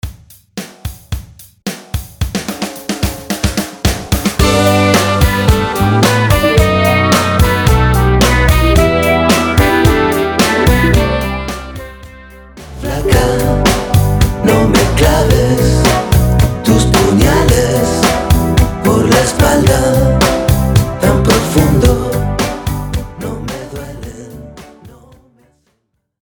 Latin music remixes
high-energy beats